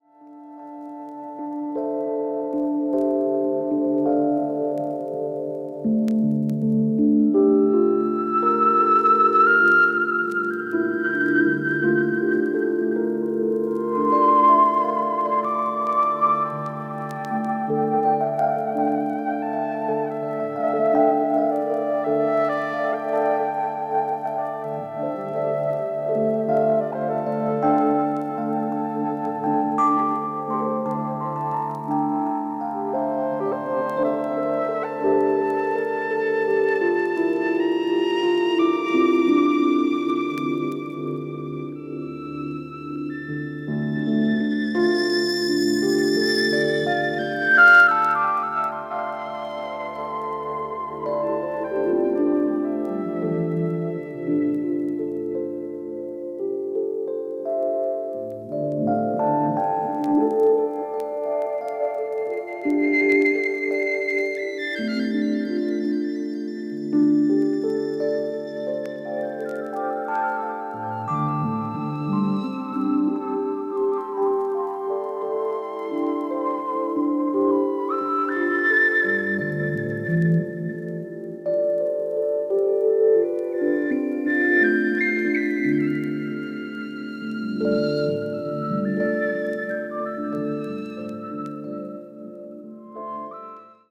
二人の透明感のあるサウンドは心地良いですね～。